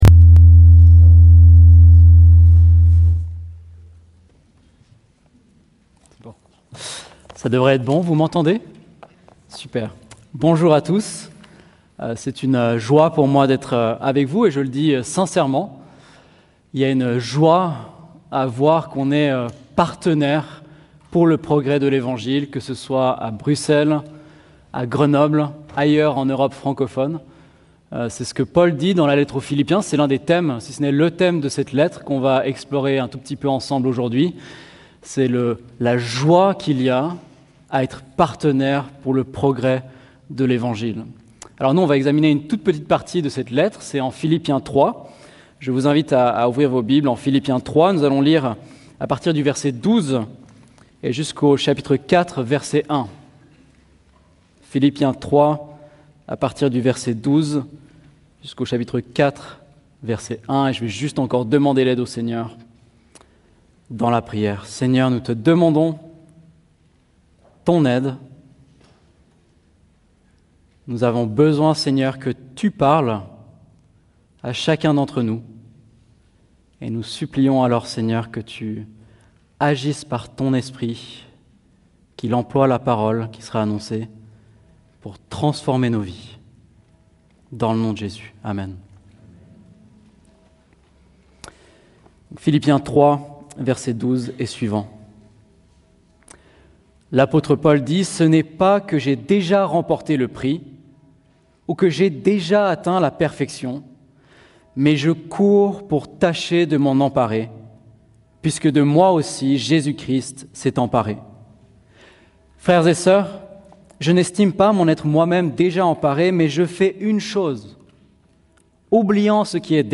Télécharger le fichier audio Texte : Philippiens 3.12-4.1 Plan de la prédication : 1.